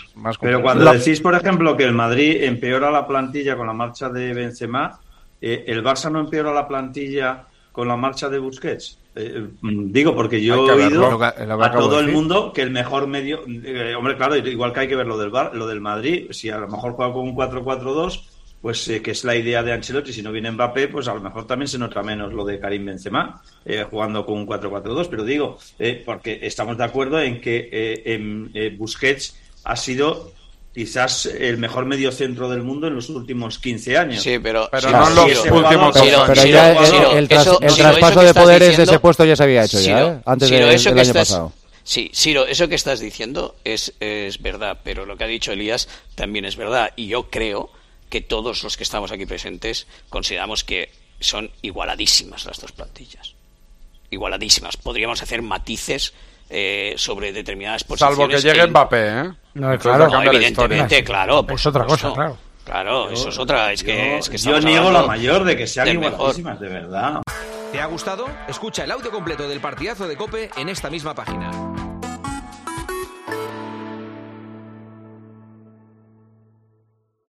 Los tertulianos de El Partidazo de COPE analizan las plantillas de ambos equipos tras las primeras salidas y los primeros fichajes confirmados.